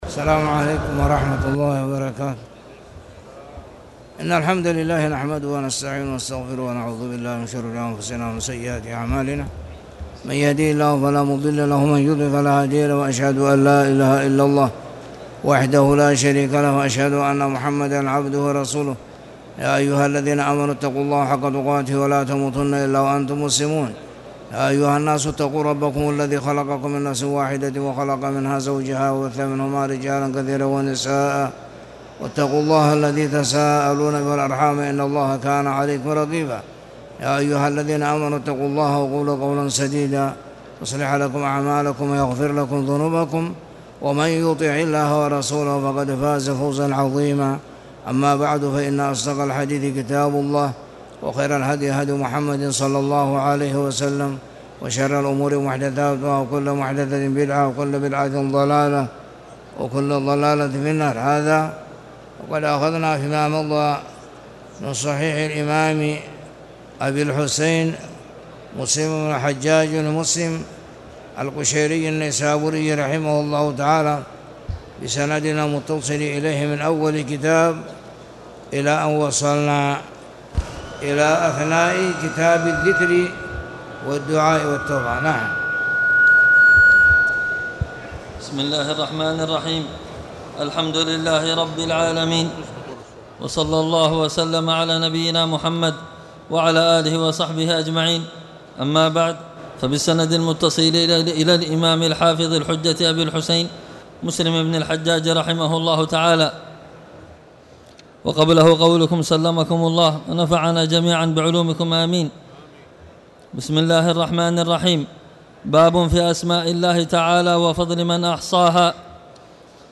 تاريخ النشر ٣٠ جمادى الأولى ١٤٣٨ هـ المكان: المسجد الحرام الشيخ